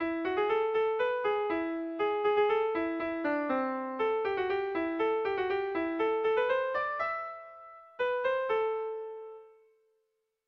Erromantzea
Kopla handia
ABD